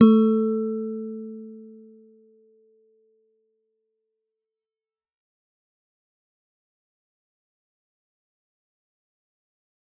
G_Musicbox-A3-f.wav